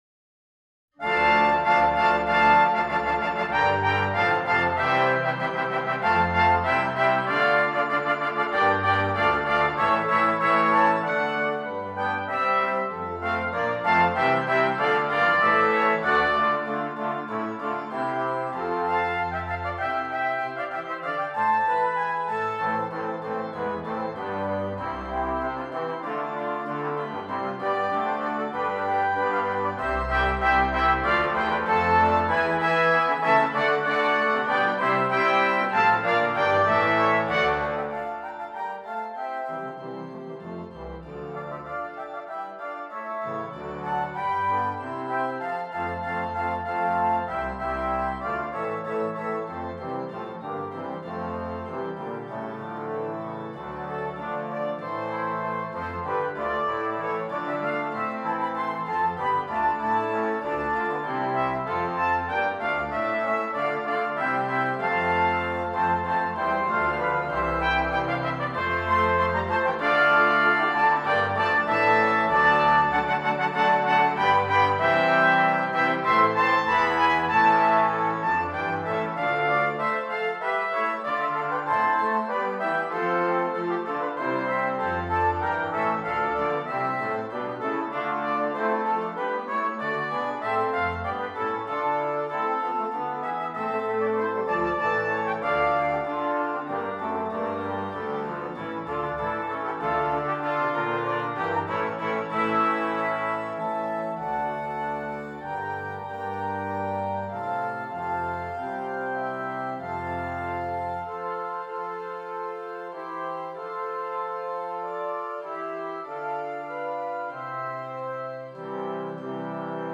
Brass Choir (2.0.4.0.0.organ)